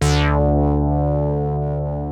OSCAR 8 D3.wav